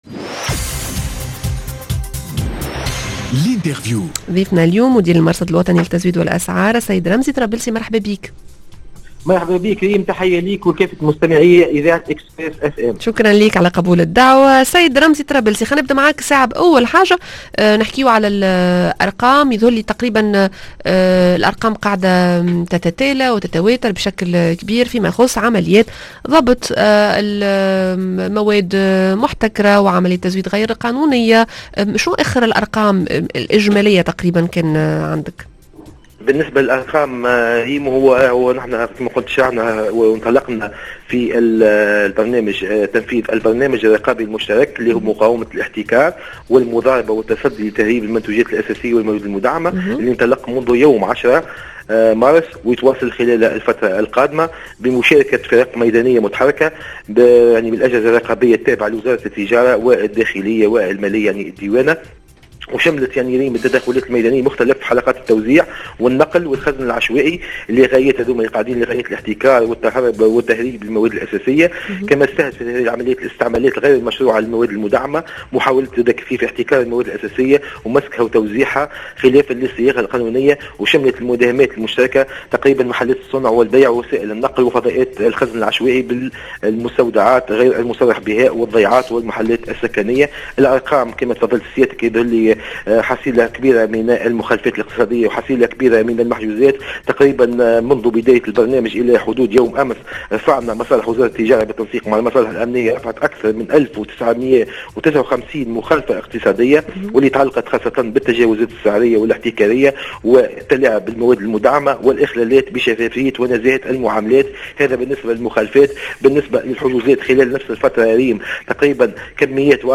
L'interview: احتكار و تخزين و حملات اقتصادية , و النتيجة ؟